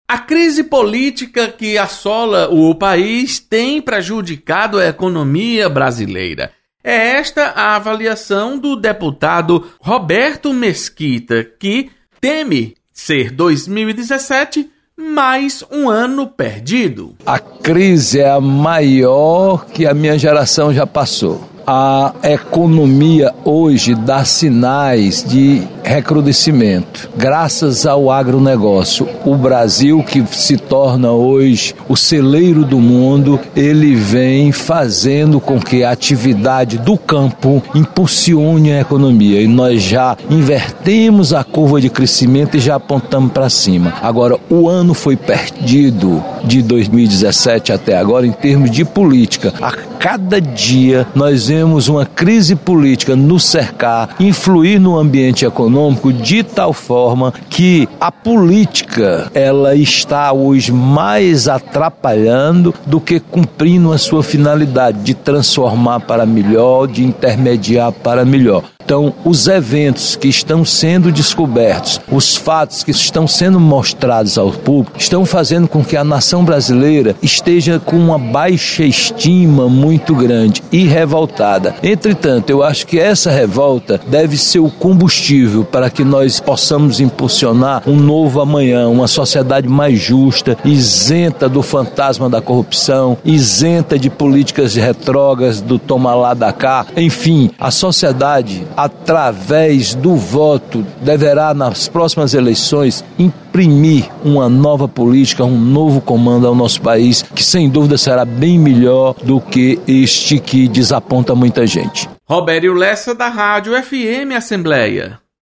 Deputado Roberto Mesquita teme ser 2017 um ano perdido para a economia brasileira. Repórter